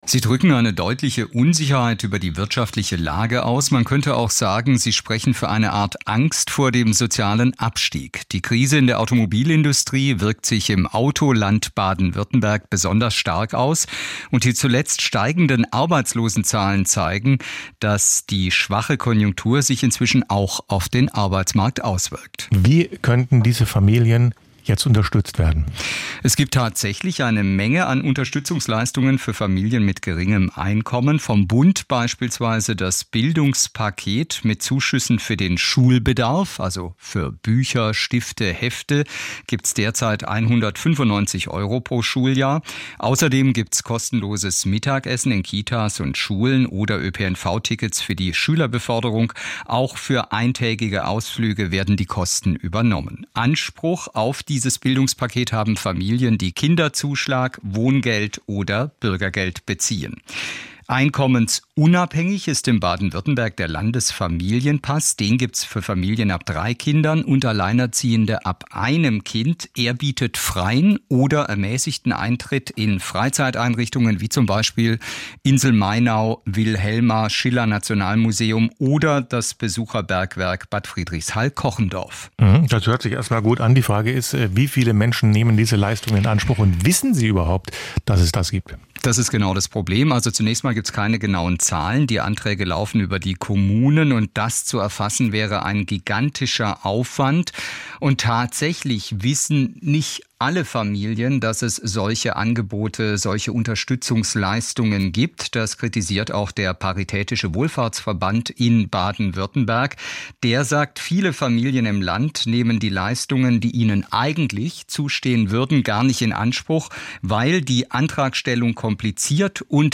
in SWR1 gesprochen: